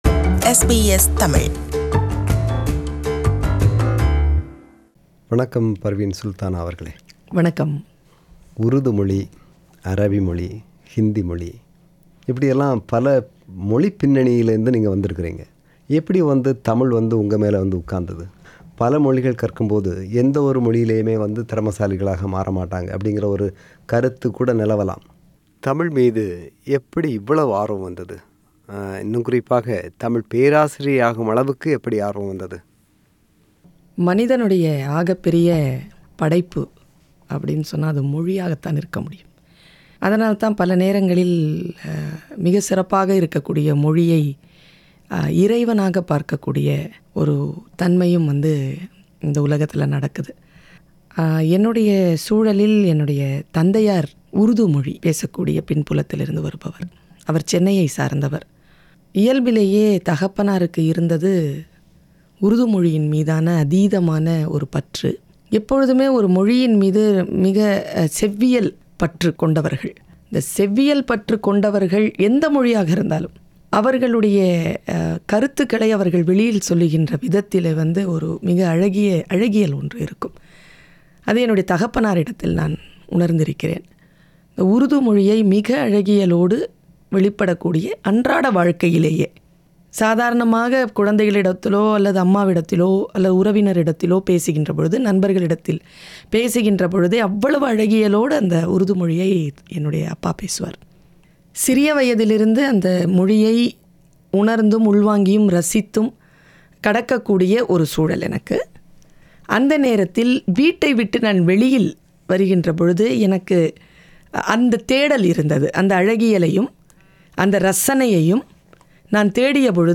Interview with Prof. Parveen Sultana – Part 1